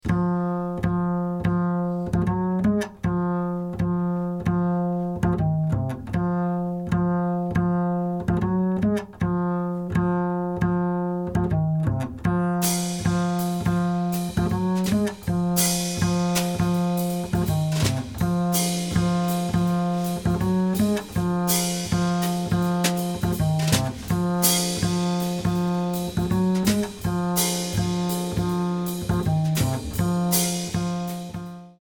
A catchy medium-up groover with a slightly mysterious mood.
mp3 Bass & Drums only